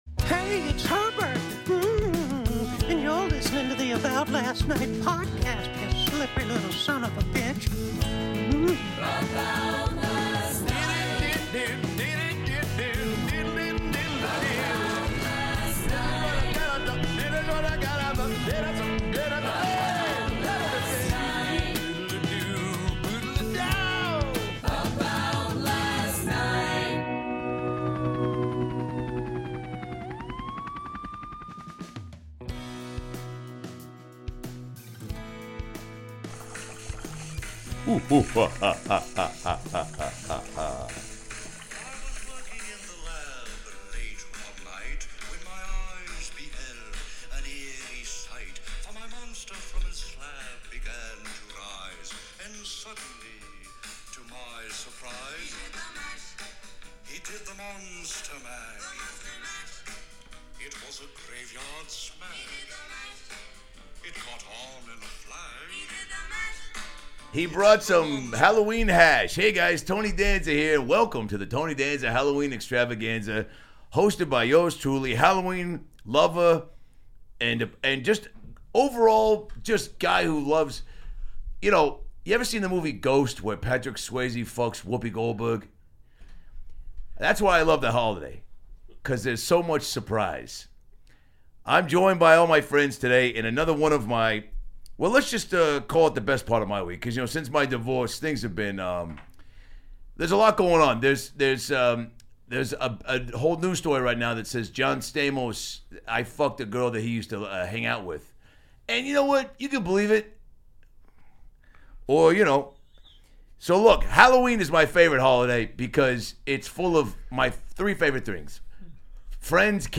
Fully Improvised Show